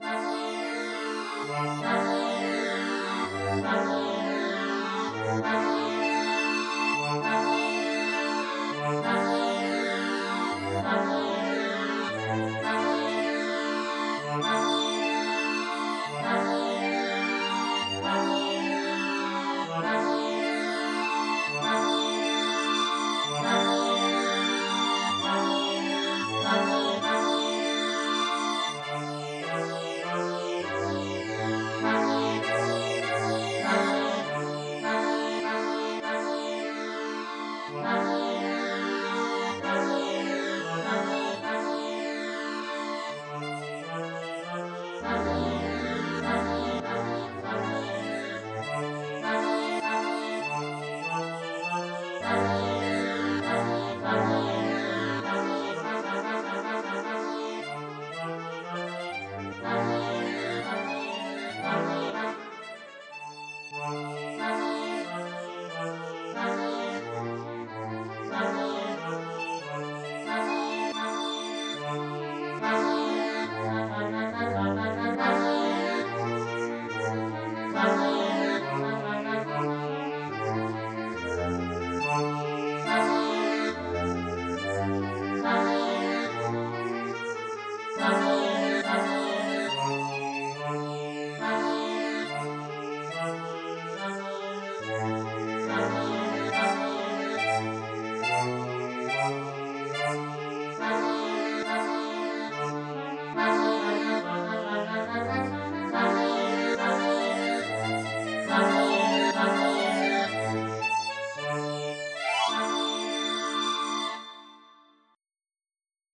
Ноты для баяна, аккордеона
*.mid - МИДИ-файл для прослушивания нот.